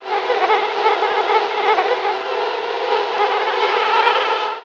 Bees Buzzing, Swarming